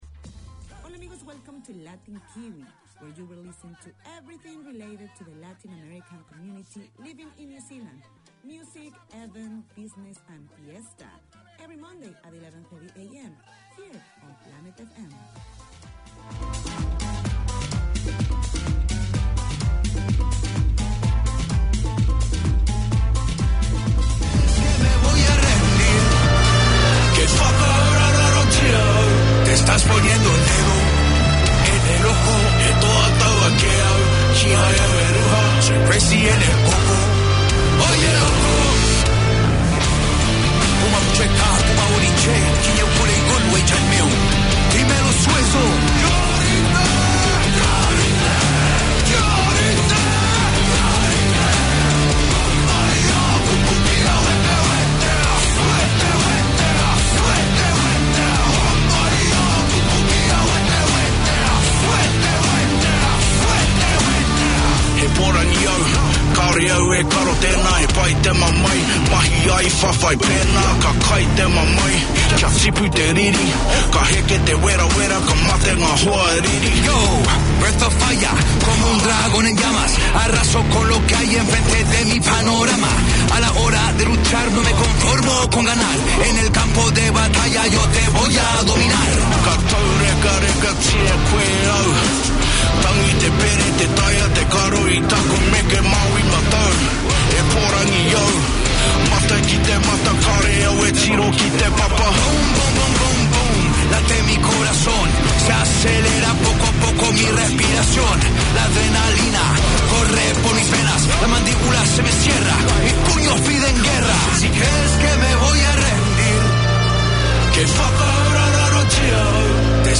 Latin Kiwi 4:25pm WEDNESDAY Community magazine Language: English Spanish Bienvenidos a todos!